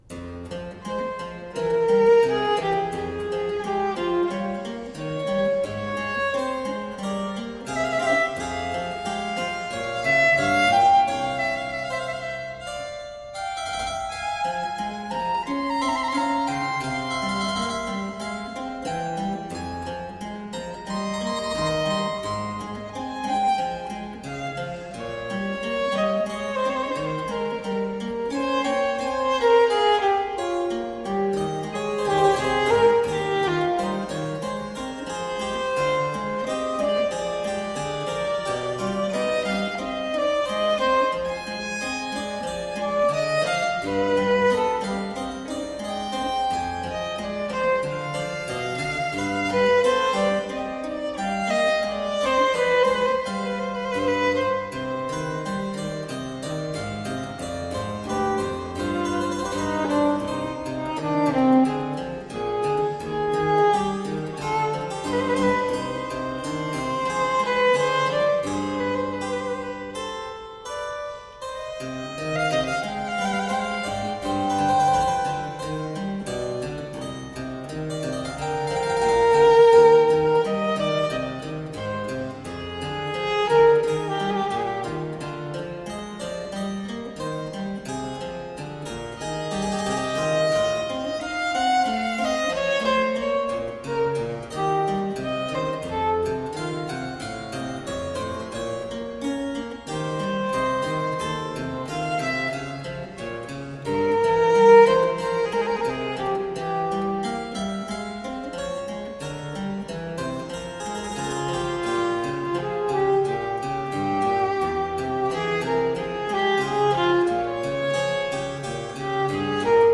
Baroque violin & harpsichord.